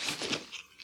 equip_diamond6.ogg